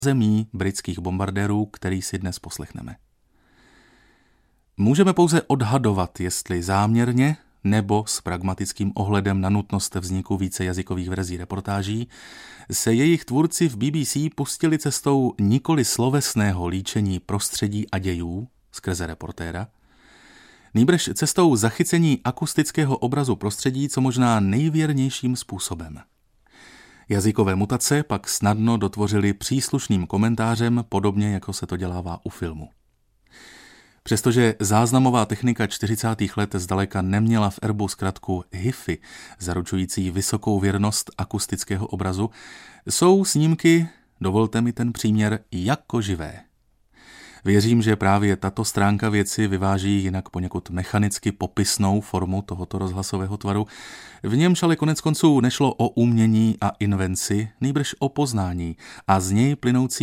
Audiobook
Audiobooks » Theater, Radio, Television